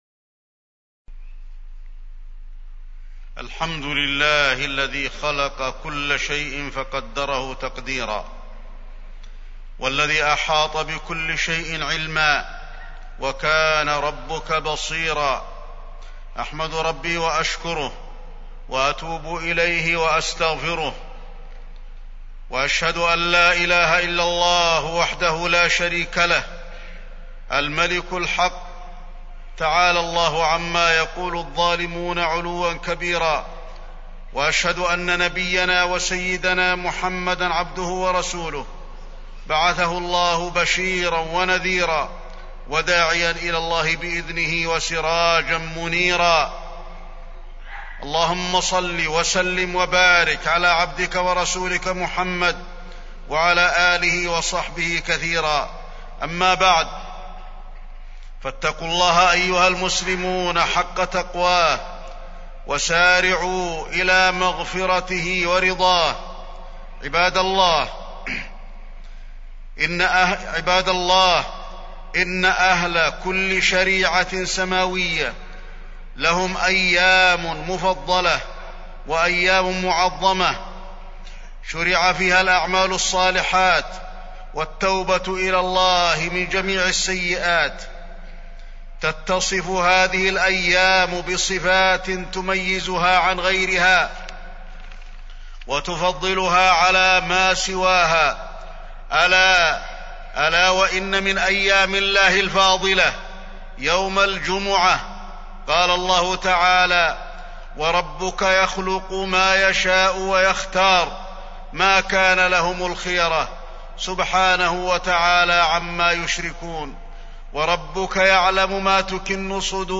تاريخ النشر ٣٠ ربيع الأول ١٤٢٧ هـ المكان: المسجد النبوي الشيخ: فضيلة الشيخ د. علي بن عبدالرحمن الحذيفي فضيلة الشيخ د. علي بن عبدالرحمن الحذيفي فضل يوم الجمعة